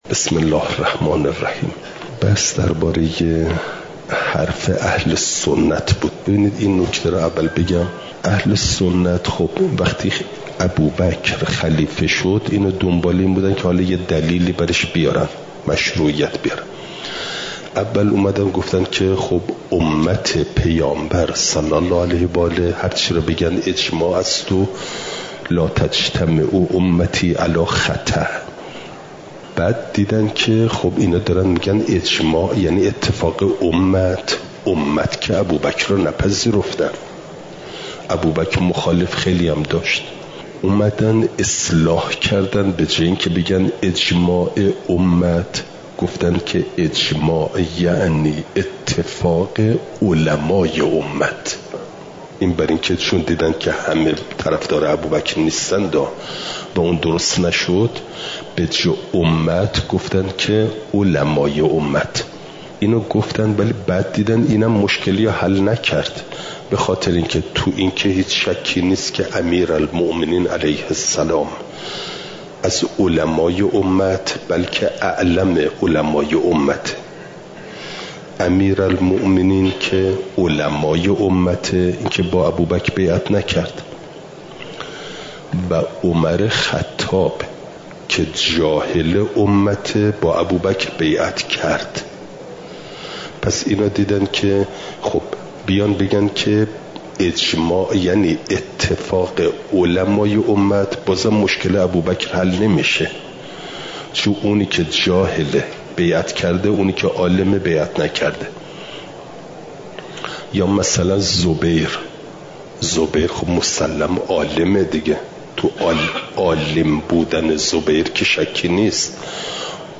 امارات؛ قطع و ظن (جلسه۸۸) – دروس استاد